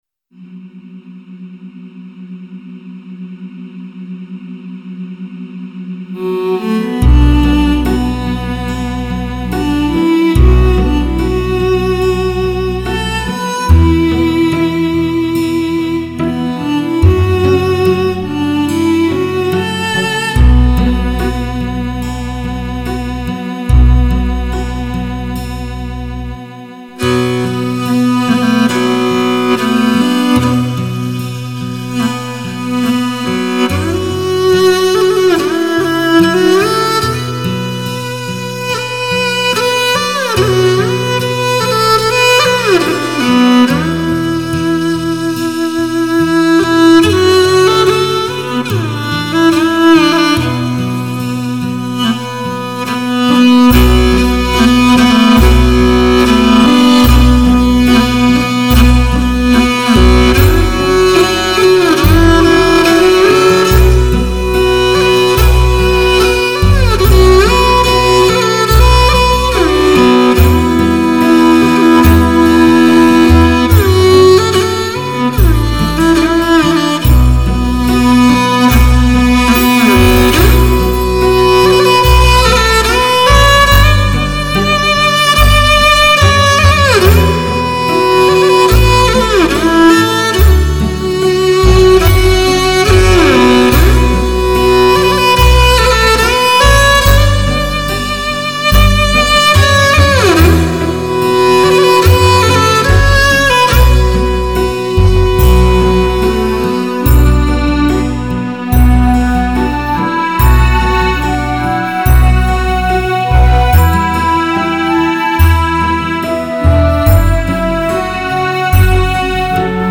3周前 纯音乐 6